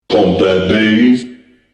Scratch voice soundbank 2
Free MP3 scratch Dj's voices sound effects 2